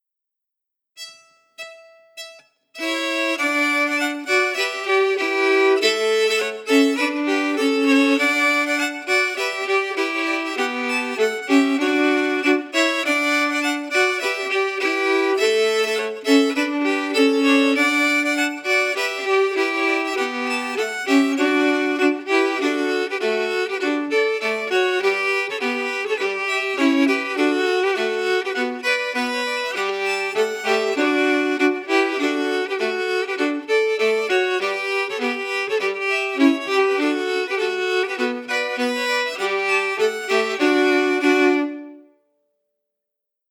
Key: D
Form: March or reel
Harmony emphasis